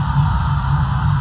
SPACECRAFT_Hover_02_loop_mono.wav